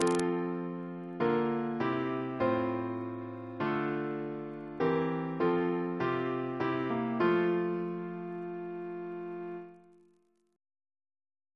Single chant in F Composer: William Russell (1777-1813) Reference psalters: ACB: 112; H1982: S422; OCB: 59 164; PP/SNCB: 5